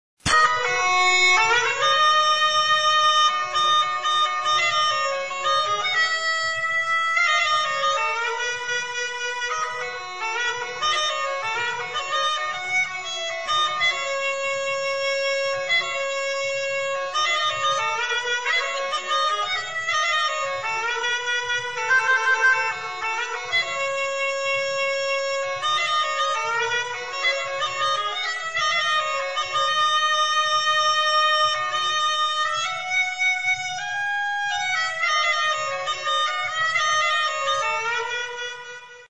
Cabrettes et Cabrettaïres le site Internet officiel de l'association de musique traditionnelle auvergnate